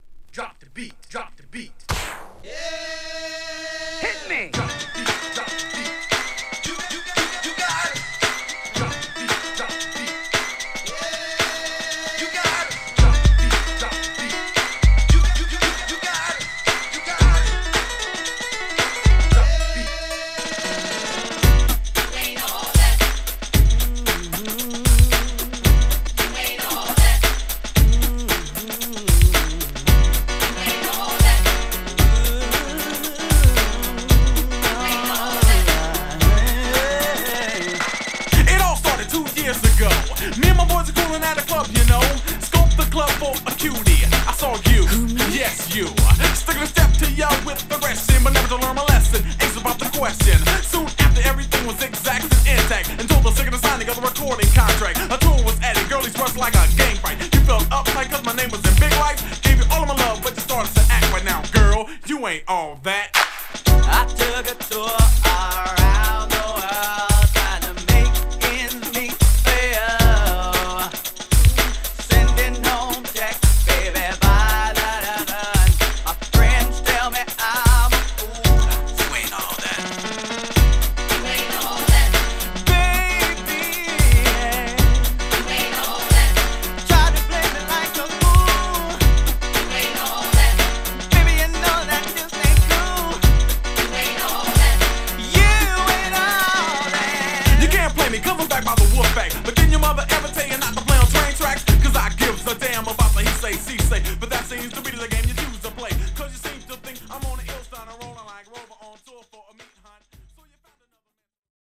破壊力抜群のトラックが魅力のアッパーなニュージャック・ナンバー!!!1991年。